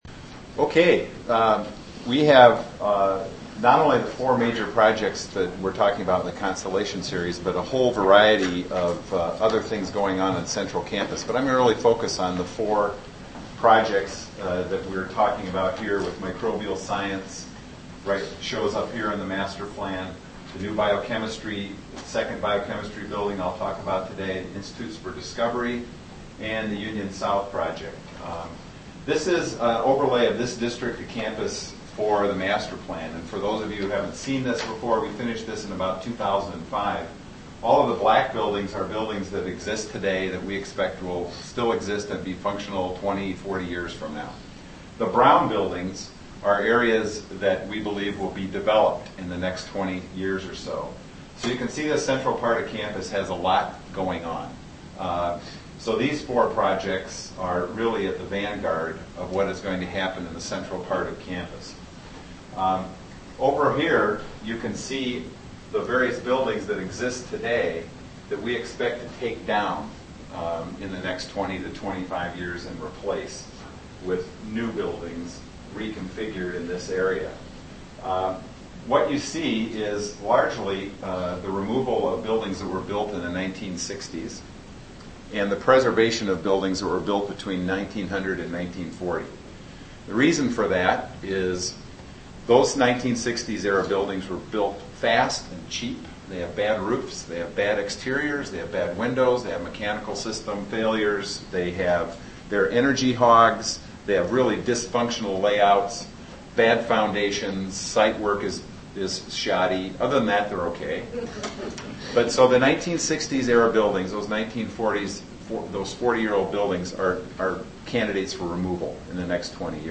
This presentation is a part of "The New Constellation" seminar series, sponsored by The Center for Biology Education, the Science Alliance at UW-Madison, and WISCAPE.